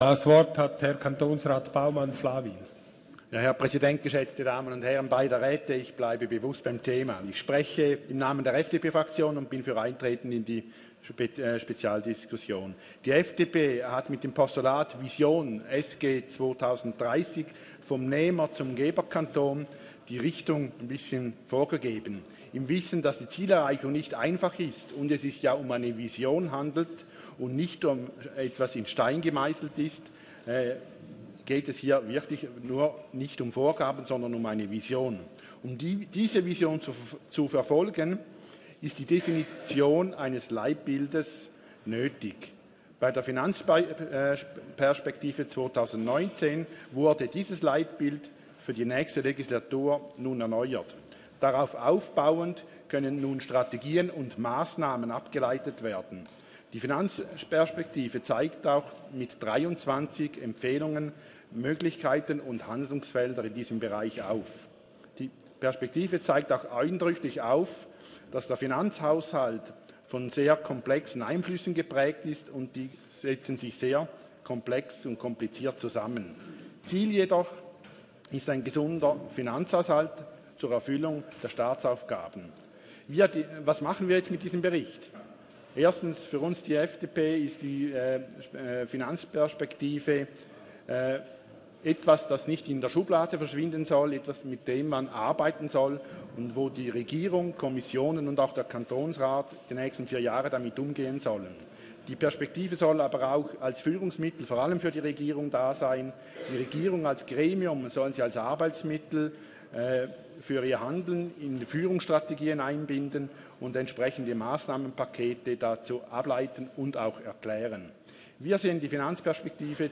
Session des Kantonsrates vom 17. bis 19. Februar 2020